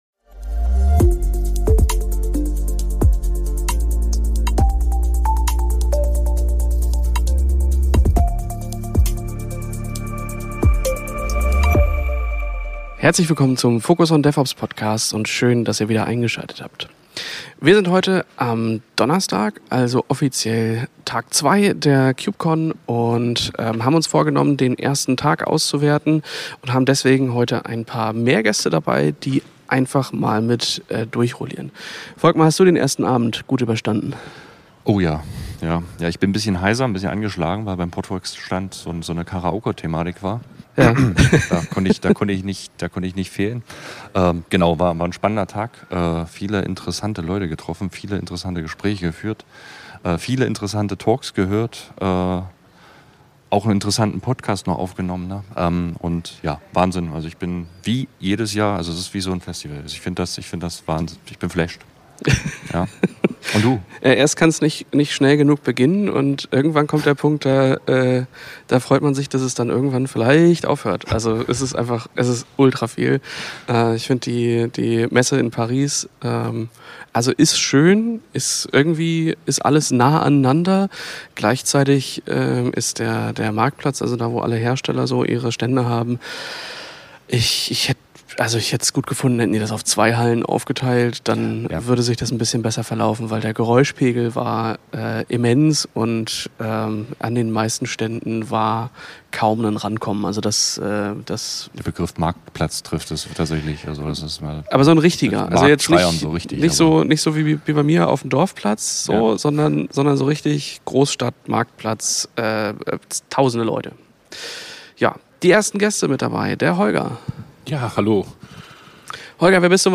Um die Vielfalt der Messe besser zu erfassen, haben sie erneut spannende Gäste eingeladen, die ihre persönlichen Höhepunkte mit uns teilen. Im Fokus stehen nicht nur die Messe und die Gemeinschaft, sondern auch der Contributor Summit, wichtige Updates zu Open Telemetry, die neuesten Entwicklungen von Kubermatic und KKP sowie die Einführung und Potenziale von WASM Edge. Seien Sie gespannt auf eine tiefgehende Diskussion über die aktuellen Trends und Innovationen in der Welt von DevOps!